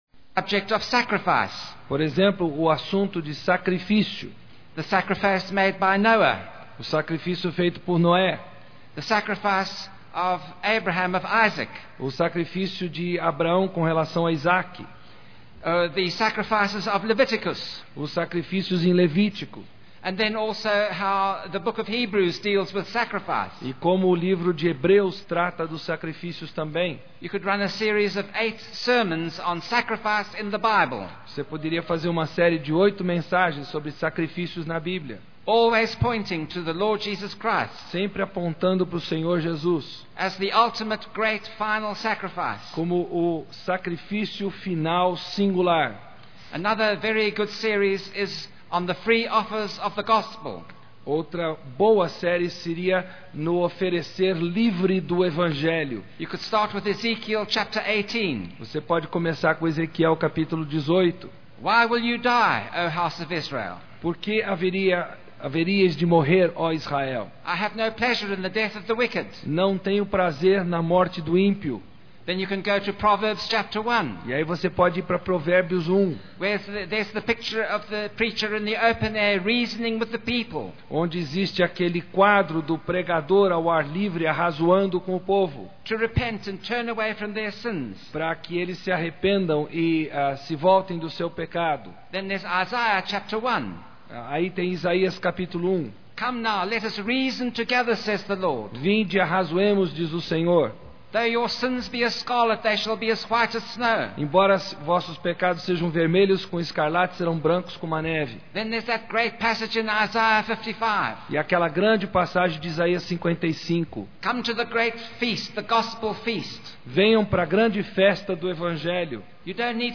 18ª Conferência Fiel para Pastores e Líderes – Brasil - Ministério Fiel